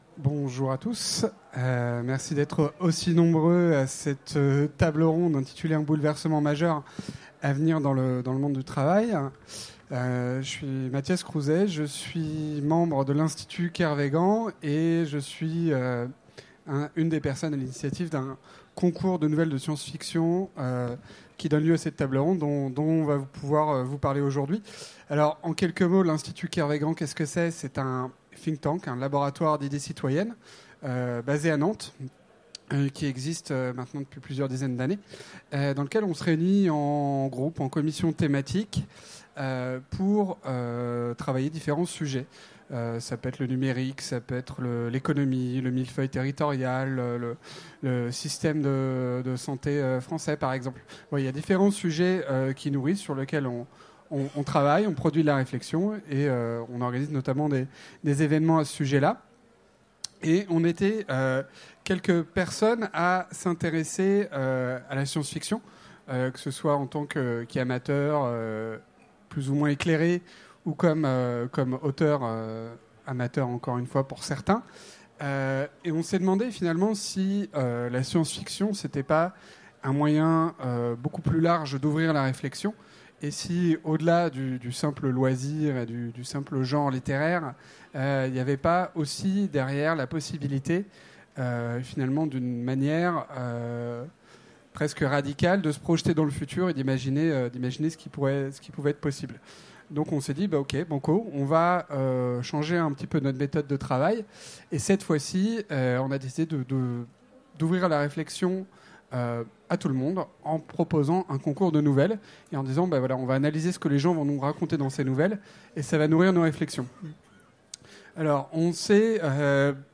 Utopiales 2016 : Conférence Un bouleversement majeur dans le monde du travail